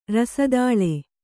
♪ rasadāḷe